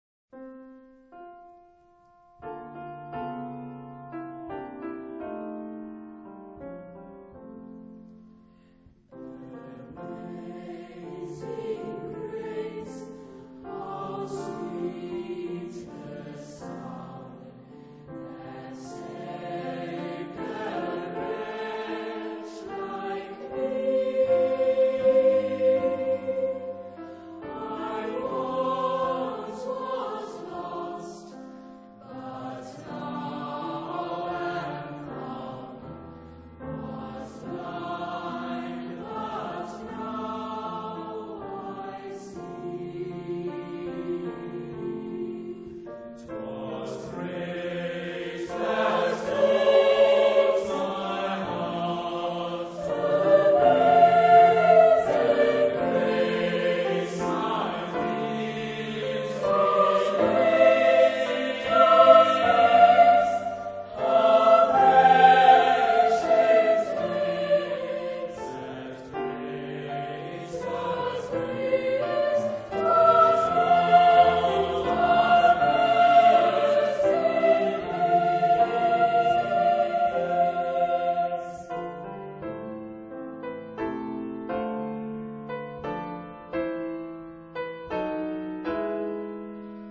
SATB (4 voices mixed) ; Full score.
Hymntune setting.
Hymn (sacred).